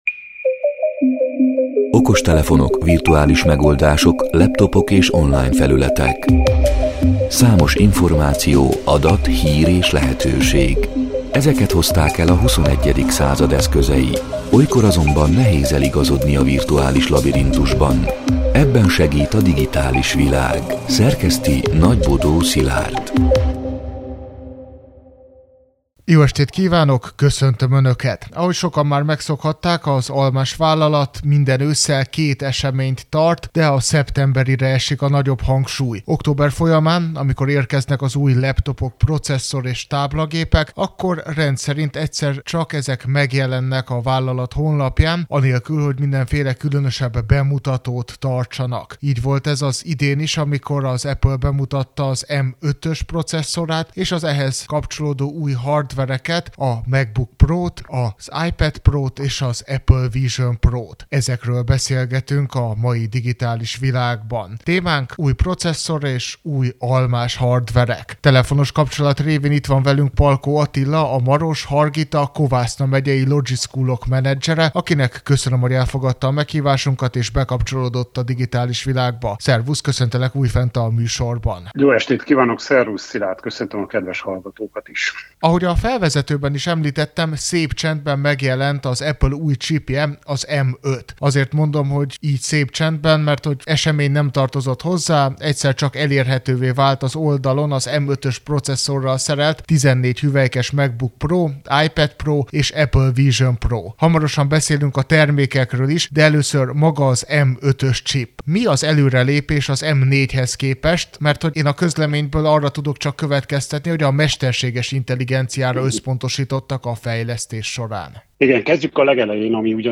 A Marosvásárhelyi Rádió Digitális Világ (elhangzott: 2025.október 28-án, kedden este nyolc órától) c. műsorának hanganyaga: